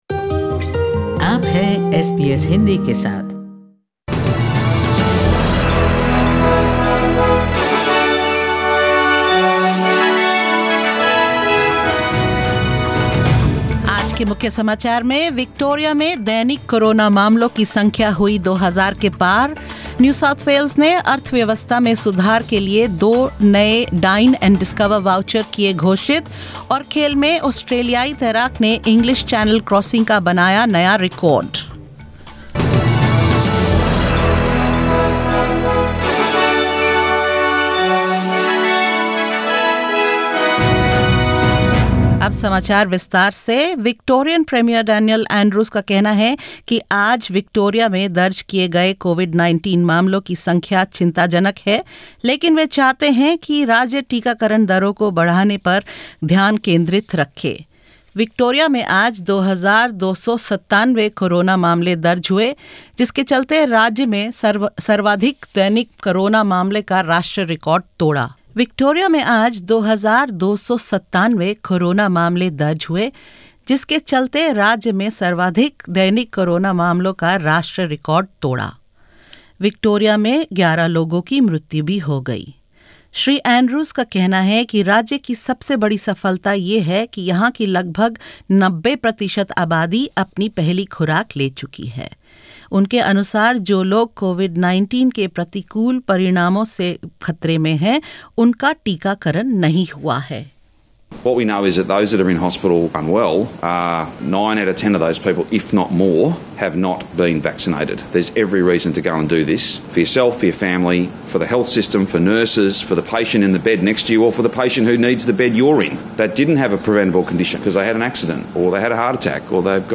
In this latest SBS Hindi News bulletin of Australia and India: With 2,297 COVID-19 cases, Victoria reports the highest number of daily cases ever recorded in Australia; Residents of New South Wales to receive Dine and Discover vouchers as the state works on economic recovery; Australian marathon swimmer Chloe McCardel breaks record for crossing the English Channel and more.